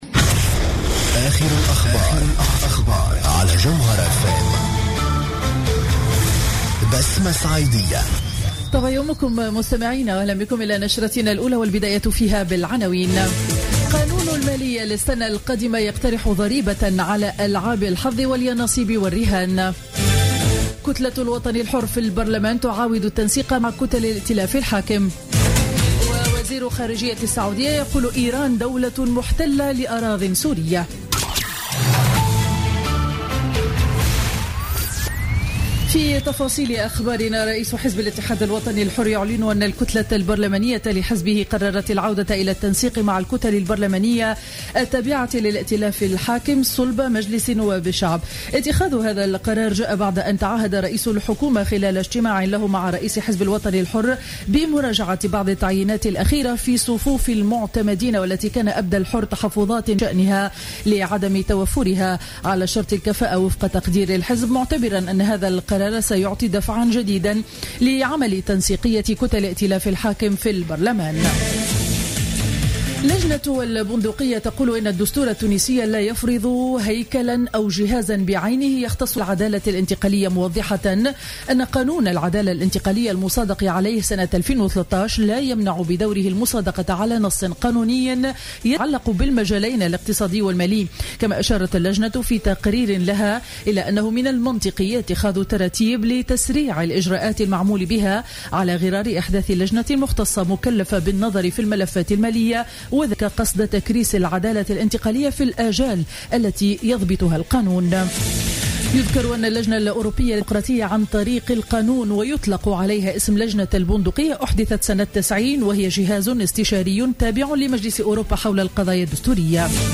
نشرة أخبار السابعة صباحا ليوم الأحد 25 أكتوبر 2015